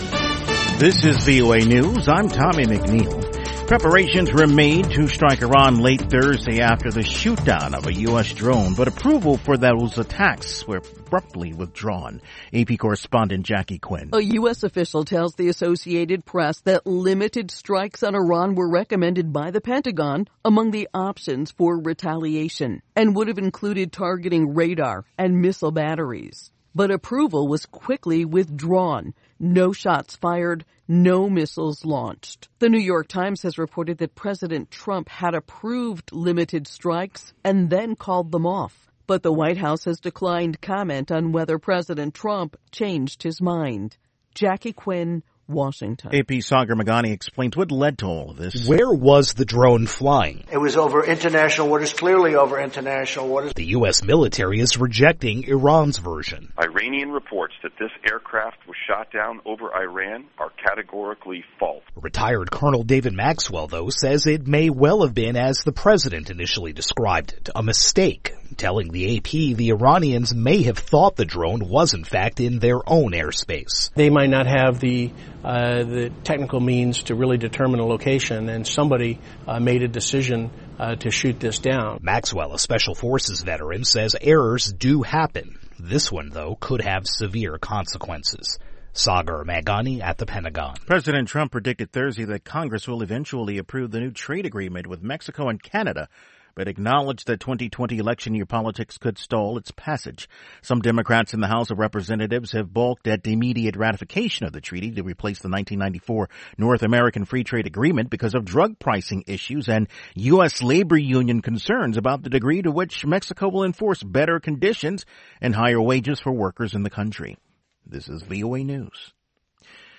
We bring you reports from our correspondents and interviews with newsmakers from across the world.
Tune in at the top of every hour, every day of the week, for the 5-minute VOA Newscast.